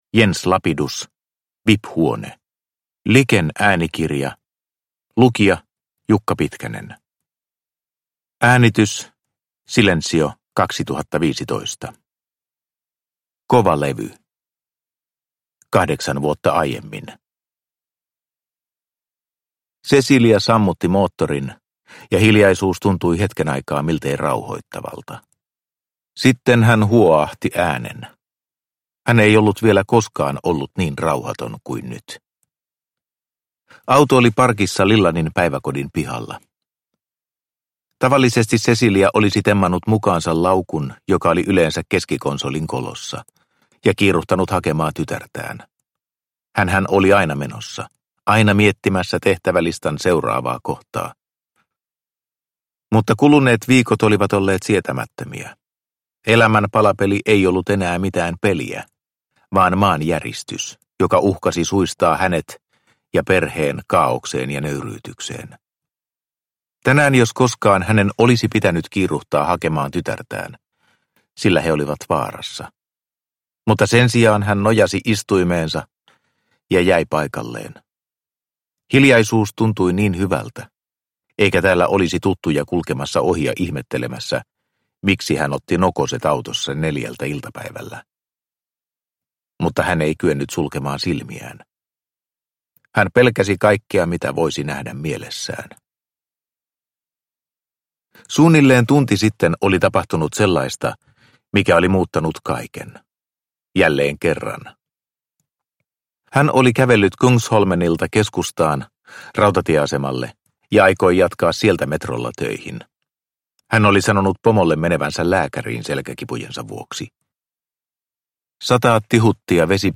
Vip-huone – Ljudbok – Laddas ner